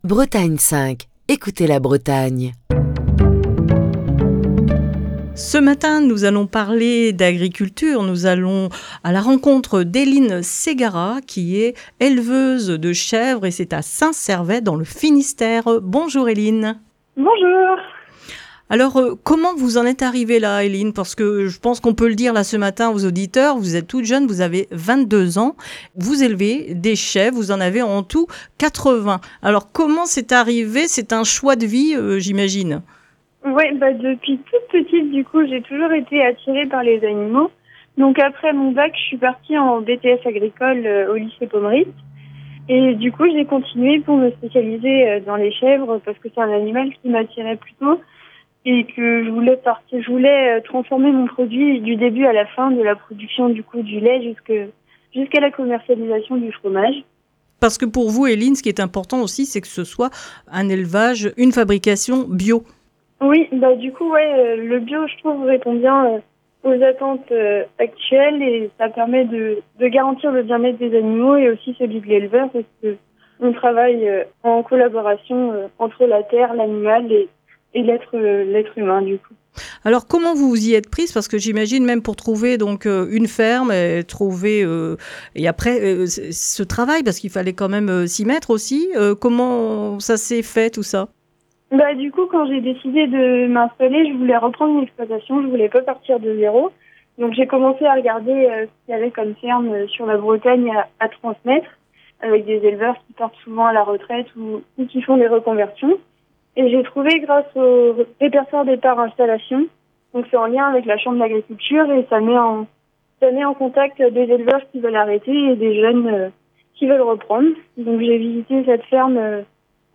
Ce jeudi, dans le coup de fil du matin